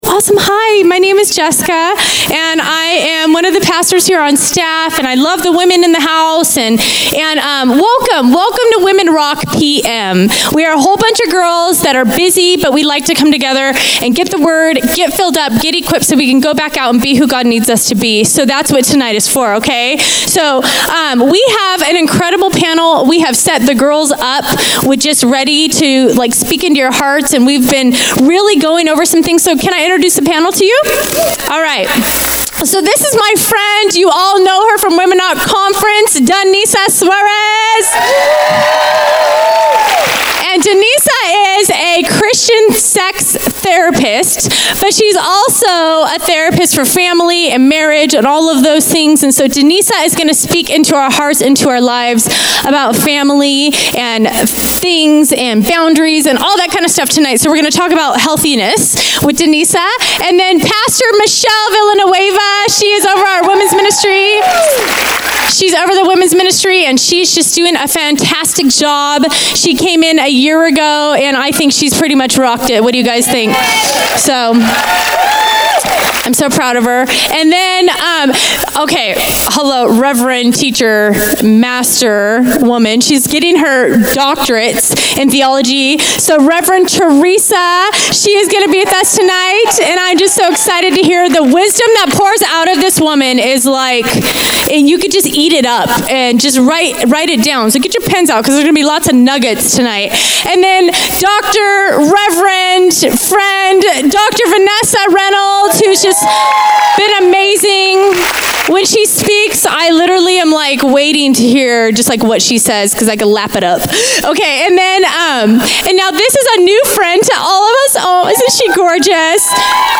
Women Rock Fall Panel